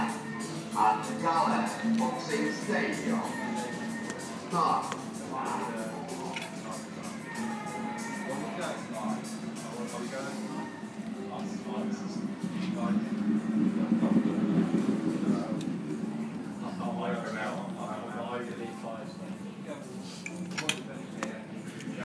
치앙마이에서 복싱 보러 오세요라는 방송을 듣는 게 익숙해진 내가 새삼스럽다.